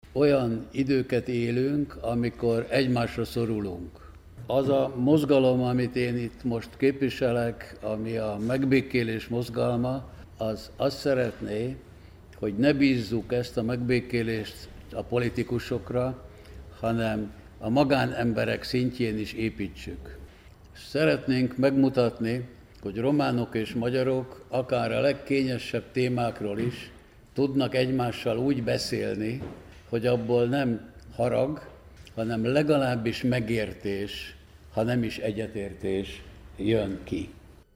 ?Ne bízzuk a megbékélést a politikusokra, hanem a magánemberekre? ? jelentette ki Surján László, aki szombaton az általa alapított Charta XXI Mozgalom szervezésében vett részt egy aradi, a román?magyar viszonyról rendezett konferencián. Ezen román és magyar értelmiségiek beszéltek az Erdély és Románia egyesülését kimondó 1918. december elsejei Gyulafehérvári Nyilatkozat közelgő centenáriumáról, és az ennek kapcsán a román társadalomban felerősödő magyarellenességről.